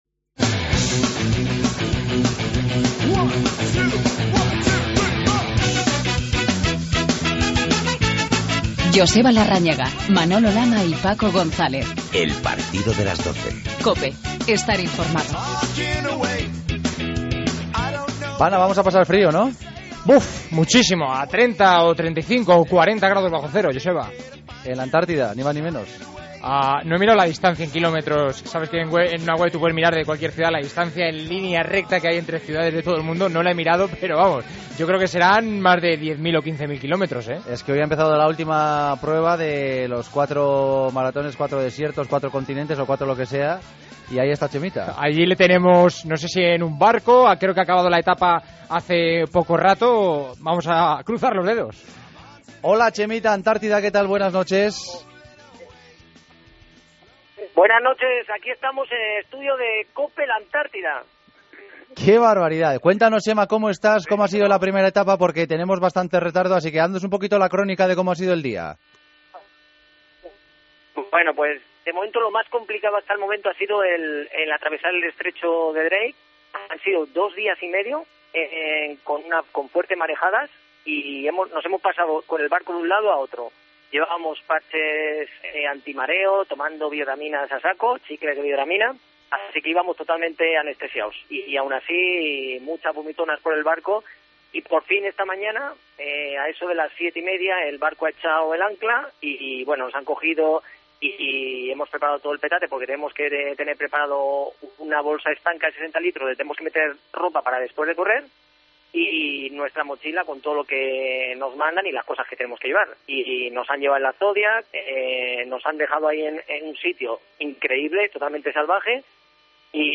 AUDIO: Hablamos con Chema Martínez a bordo de un barco en la Antártida, donde compite en una dura etapa del Grand Slam 4 Deserts.